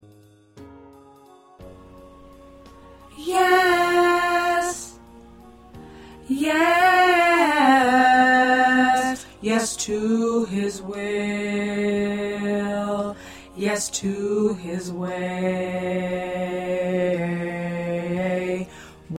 4 parts — Soprano, Alto, 1st Tenor, 2nd Tenor